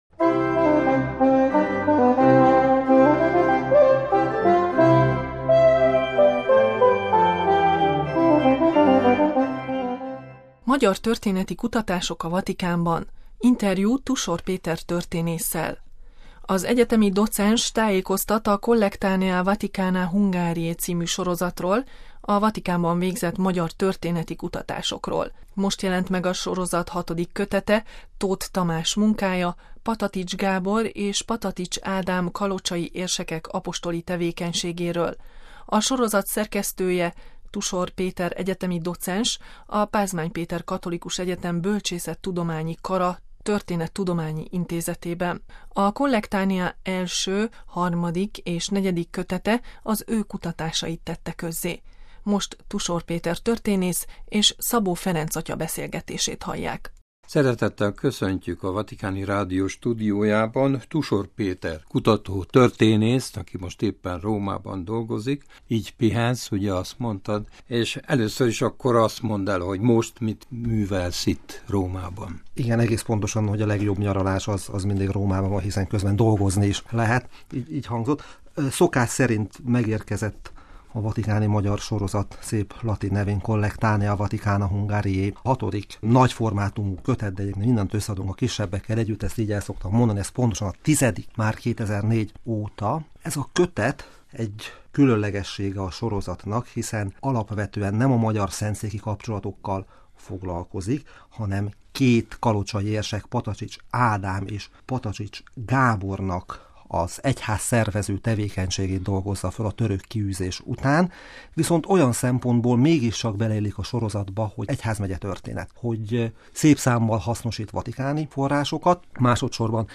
Magyar történeti kutatások a Vatikánban – interjú